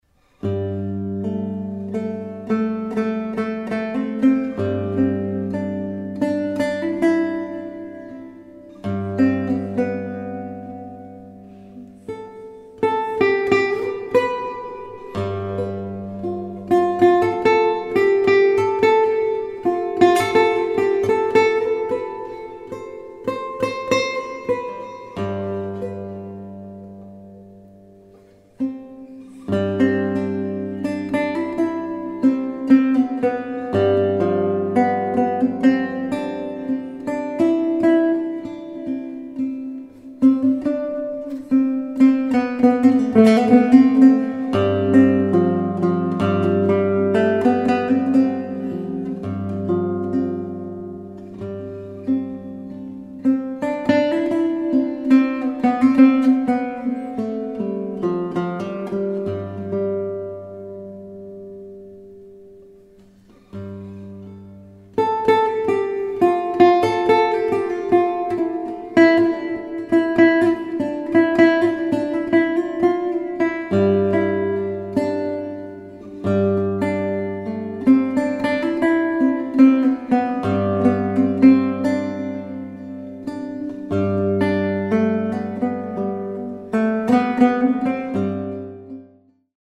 , Lute , Relaxing / Meditative